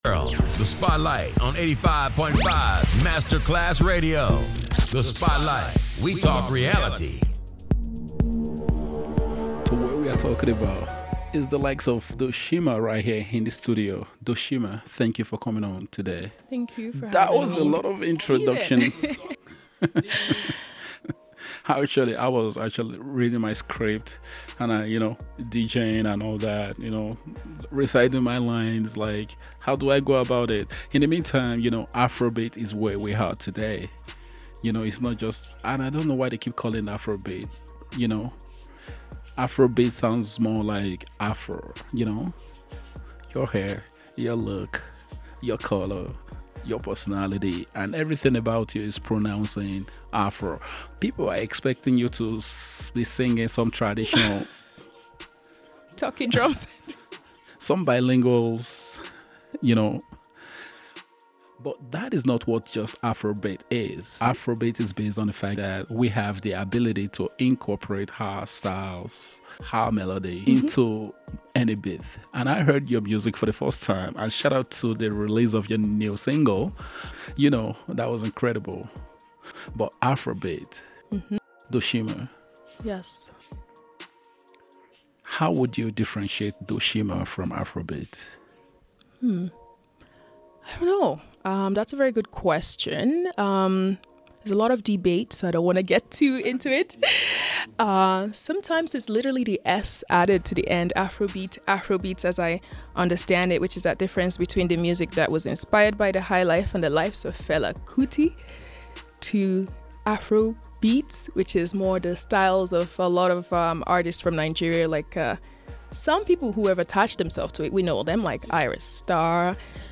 All posted contents were live broadcast and have been made available for your listening pleasure.